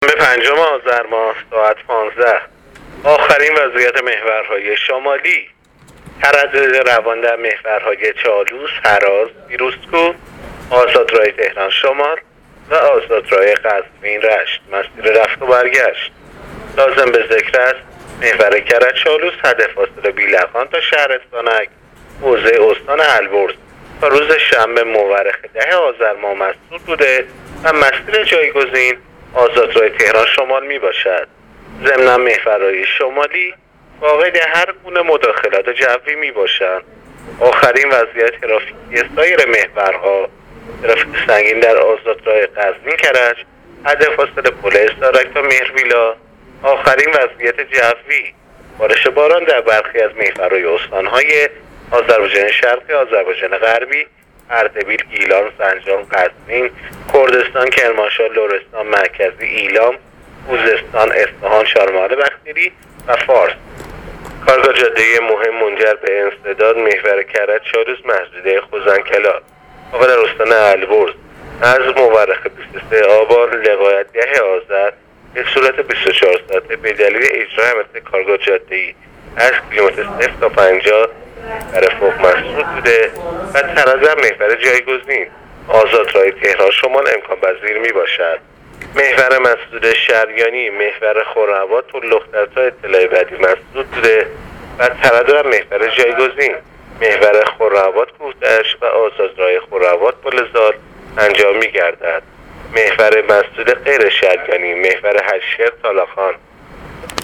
گزارش رادیو اینترنتی از آخرین وضعیت ترافیکی جاده‌ها تا ساعت ۱۵ پنجم آذر؛